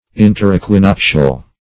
Meaning of interequinoctial. interequinoctial synonyms, pronunciation, spelling and more from Free Dictionary.
Search Result for " interequinoctial" : The Collaborative International Dictionary of English v.0.48: Interequinoctial \In`ter*e`qui*noc"tial\, a. Coming between the equinoxes.